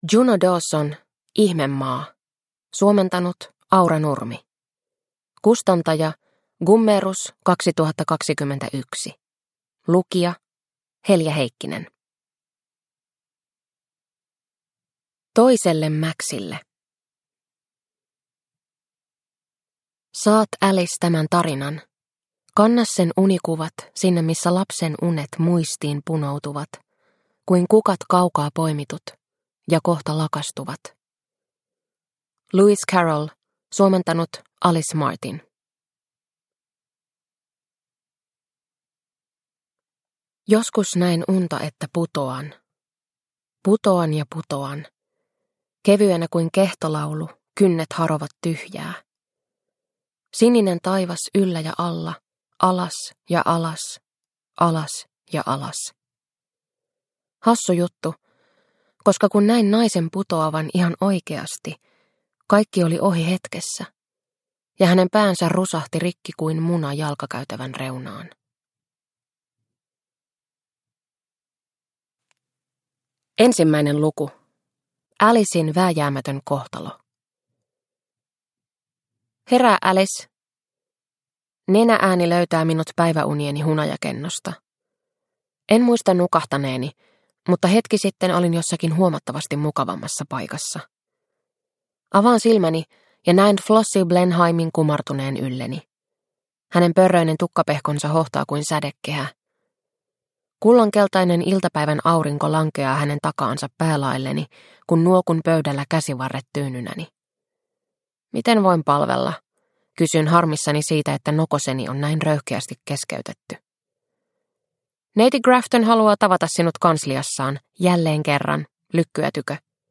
Ihmemaa – Ljudbok – Laddas ner